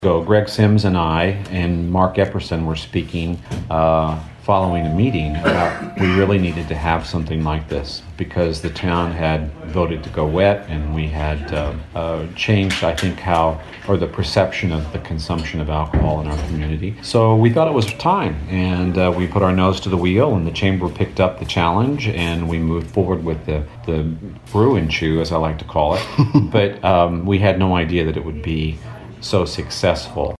Fred Vallowe, McLeansboro City Clerk, was a guest on WROY’s Open Line program recently and explained how the event came about…